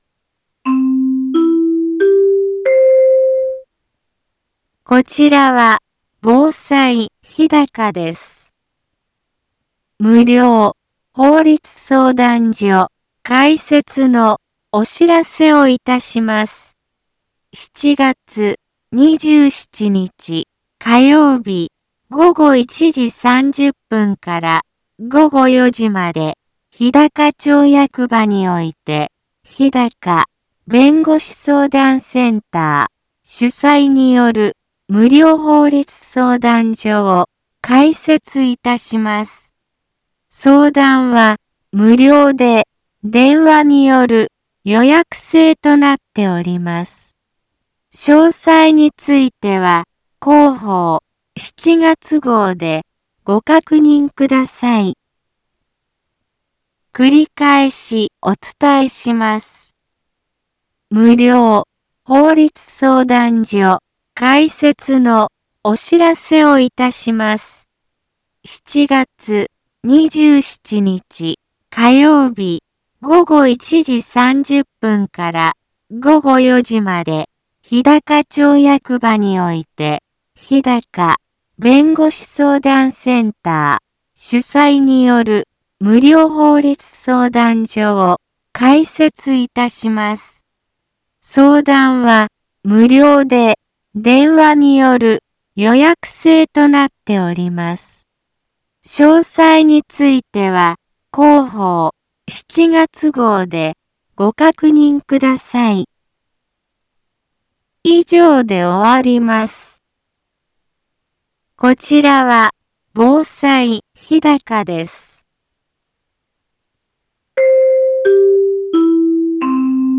Back Home 一般放送情報 音声放送 再生 一般放送情報 登録日時：2021-07-20 10:04:14 タイトル：無料法律相談会のお知らせ インフォメーション：こちらは防災日高です。 無料法律相談所開設のお知らせをいたします。